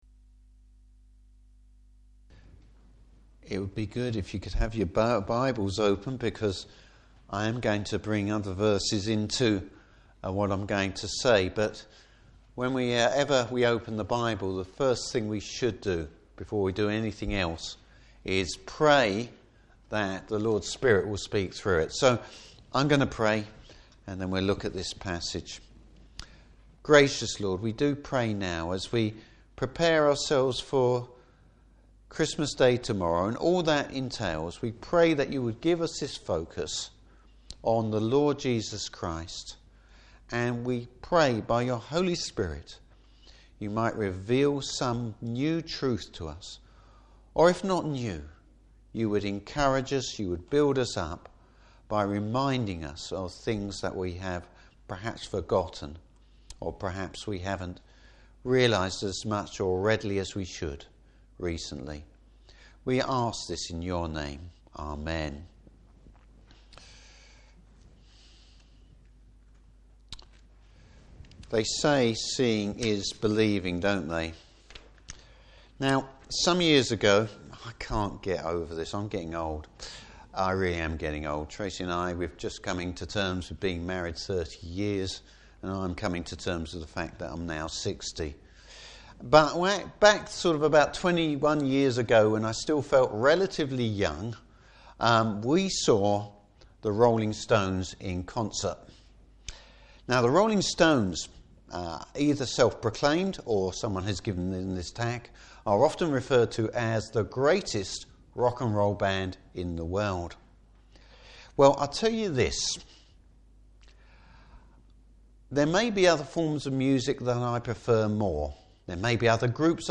Service Type: Christmas Eve Service.